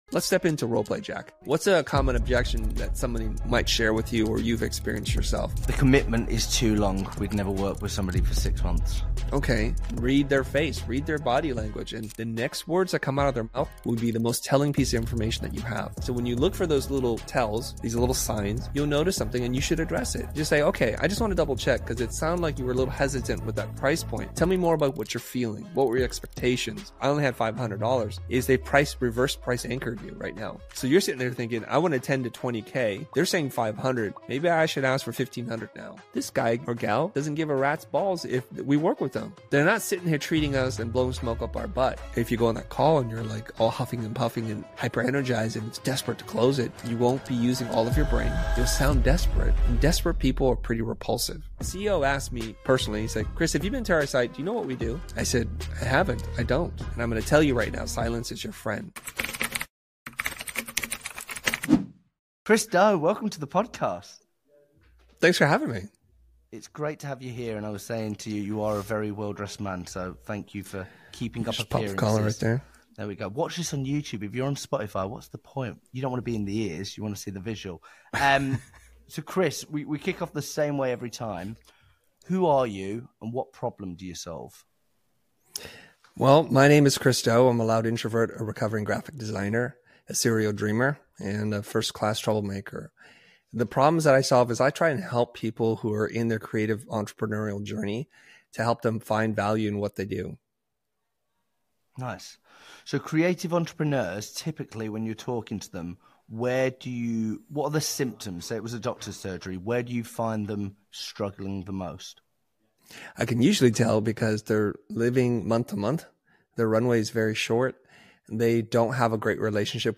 In this episode, we sit down with Chris Do, designer, educator, and founder of The Futur, to talk about why most creatives struggle with sales and how to reframe it.